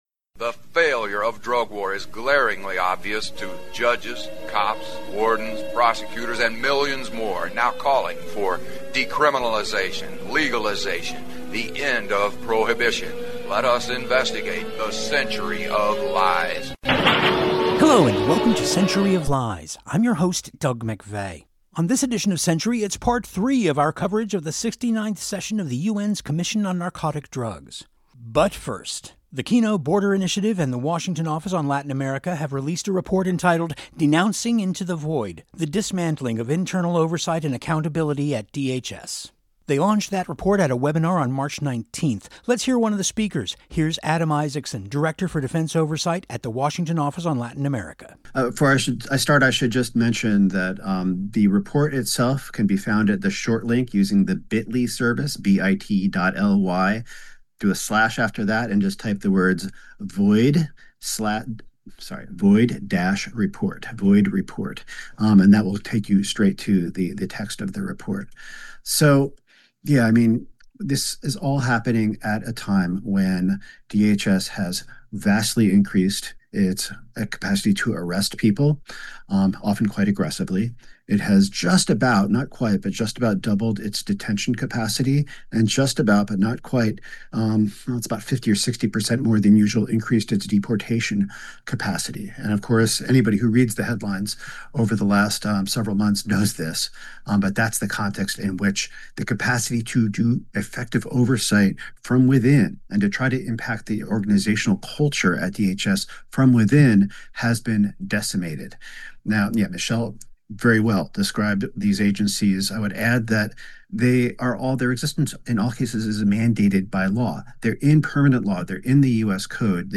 We feature voices from the 69th session of the UN’s Commission on Narcotic Drugs